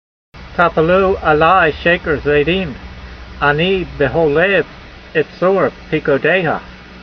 v69_voice.mp3